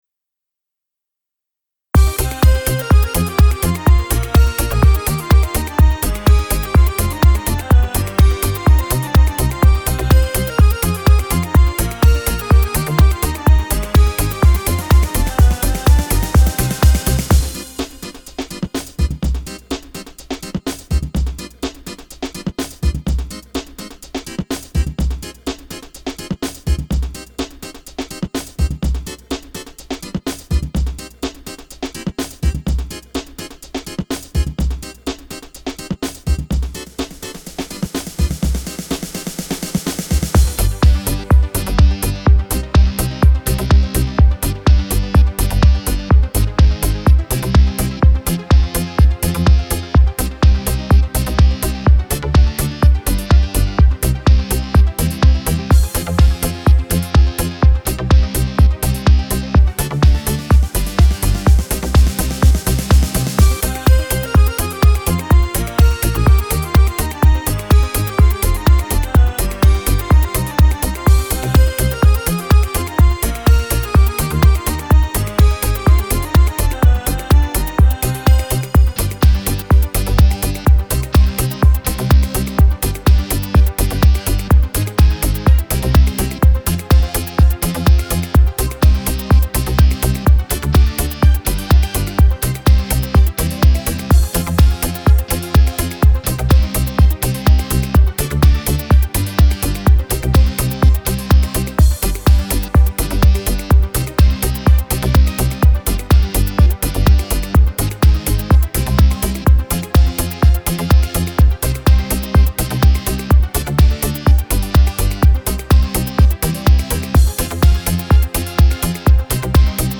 Минус Народные